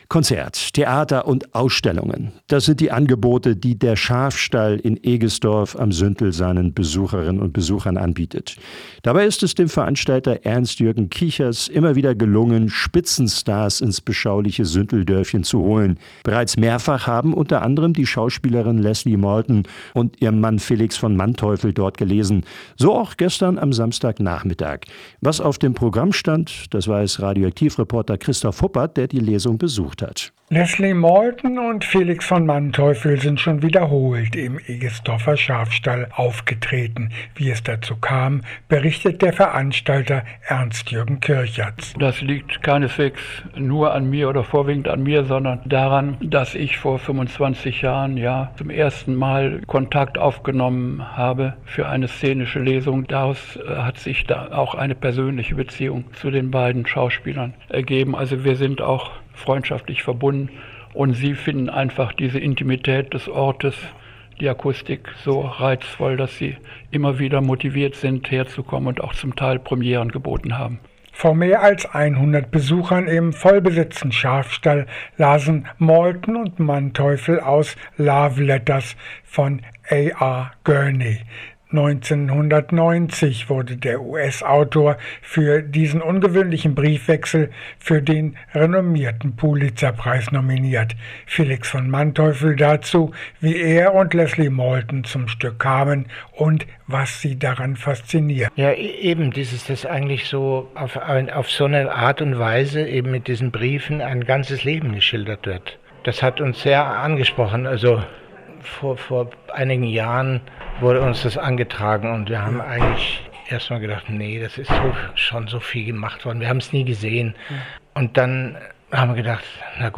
Bad Münder: Schauspiel-Stars Leslie Malton und Felix von Manteuffel haben im Egestorfer Schaafstall „Love letters“ vorgelesen